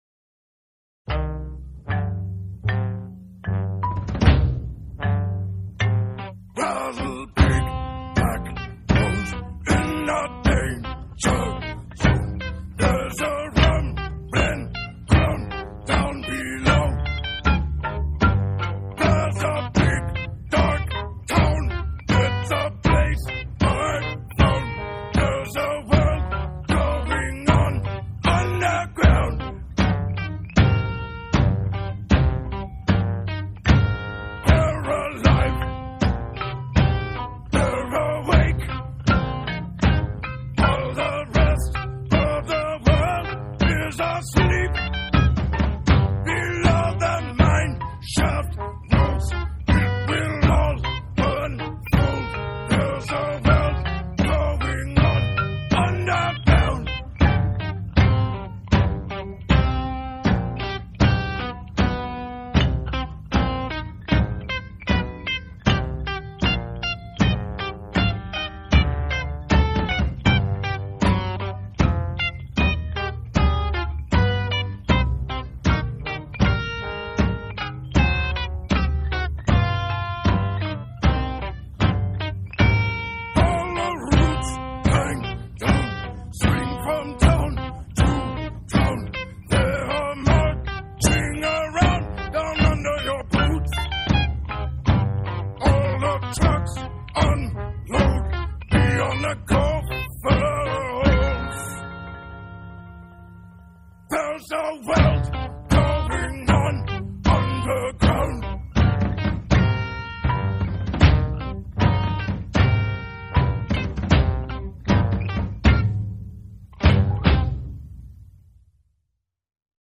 Experimental Rock, Avant-Garde